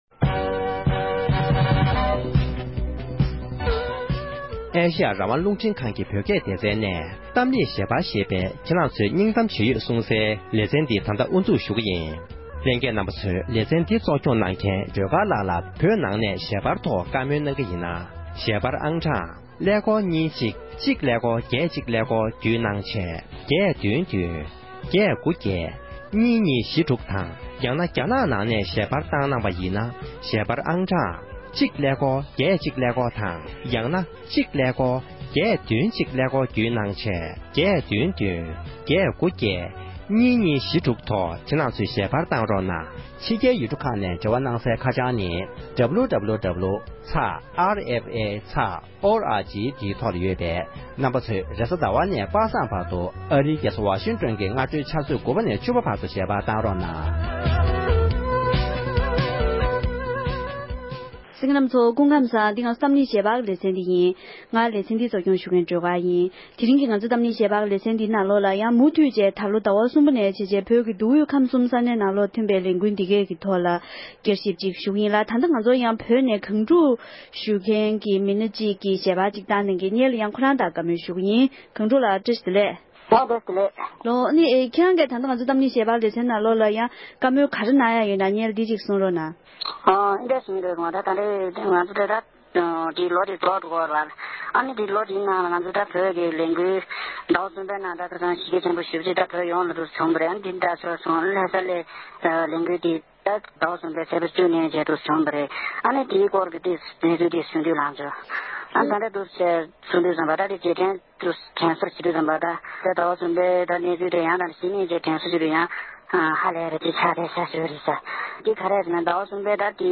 ༄༅༎དེ་རིང་གི་གཏམ་གླེང་ཞལ་པར་གྱི་ལེ་ཚན་ནང་དུ་འདི་ལོ་བོད་ཀྱི་མདོ་དབུས་ཁམས་གསུམ་ནང་ཐོན་པའི་ལས་འགུལ་ཁག་གི་ཐོག་བསྐྱར་ཞིབ་དང་ལོ་གསར་པའི་ནང་འཆར་གཞི་གང་ཡོད་སོགས་འབྲེལ་ཡོད་གནས་ཚུལ་ཁག་གི་ཐོག་བགྲོ་གླེང་ཞུས་པ་ཞིག་གསན་རོགས་གནང༌༎